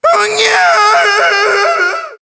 One of Funky Kong's voice clips in Mario Kart Wii